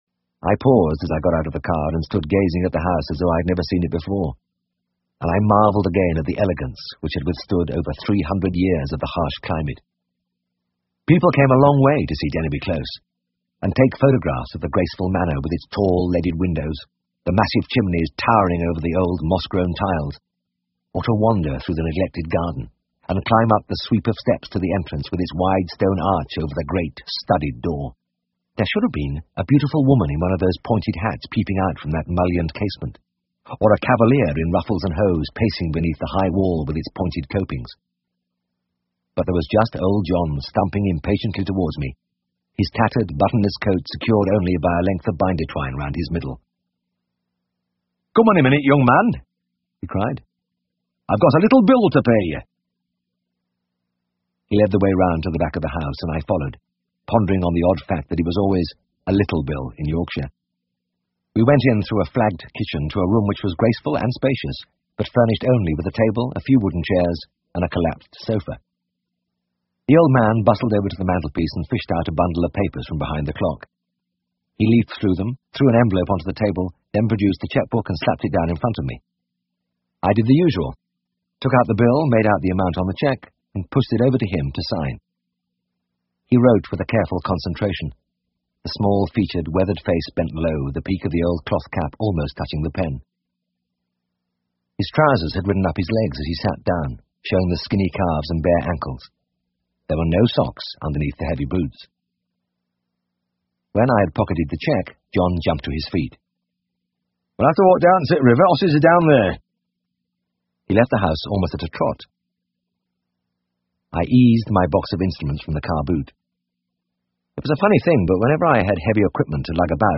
英文广播剧在线听 All Creatures Great and Small 102 听力文件下载—在线英语听力室